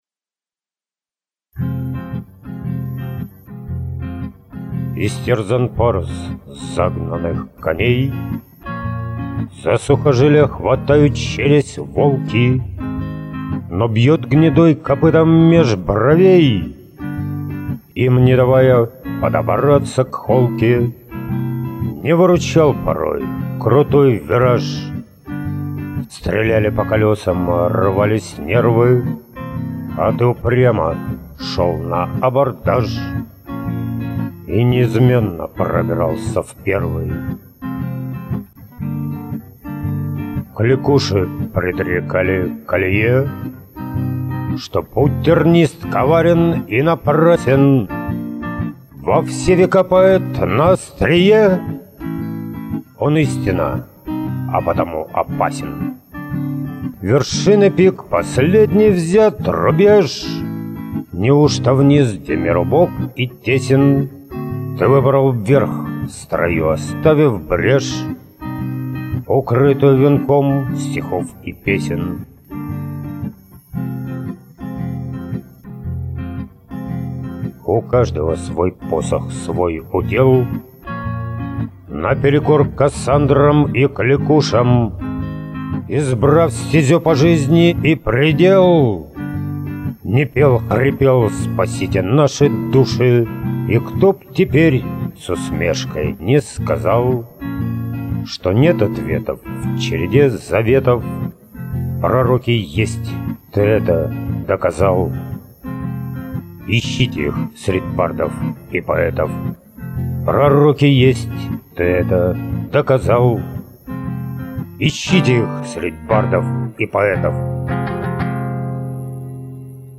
• Песня: Поэзия